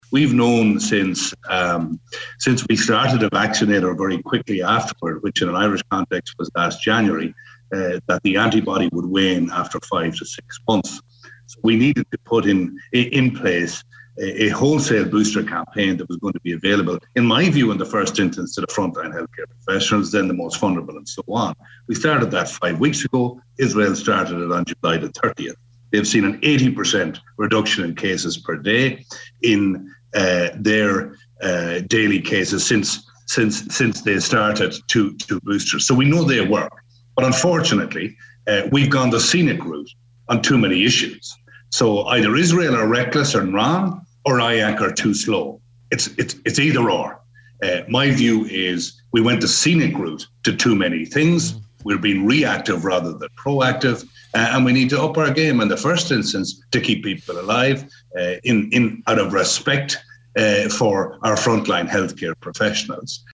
Speaking on the on The Nine Till noon Show, Deputy MCSharry referenced the swift approach by Israel and their Covid booster programme, yet this country continues to be reactive instead of proactive: